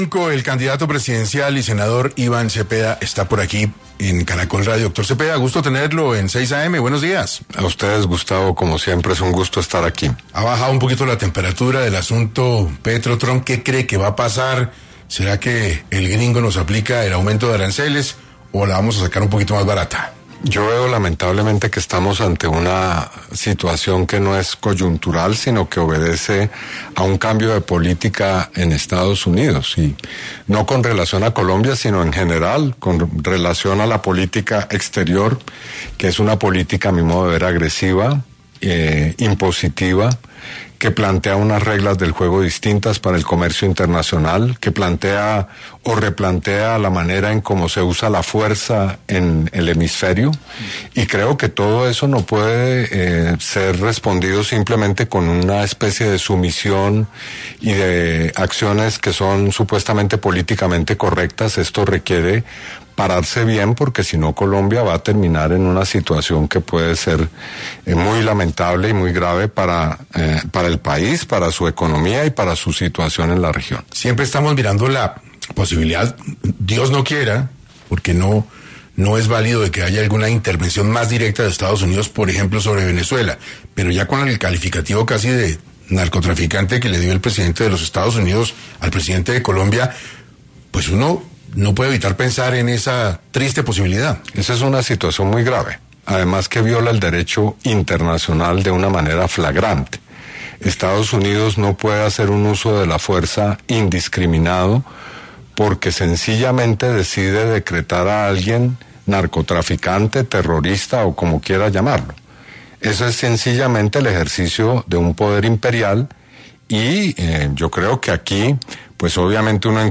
En entrevista con 6AM de Caracol Radio, el precandidato presidencial y senador Iván Cepeda abordó diversos temas, incluyendo la reciente tensión arancelaria entre Estados Unidos y Colombia, la política exterior de Donald Trump, la consulta del Pacto Histórico y su propia candidatura.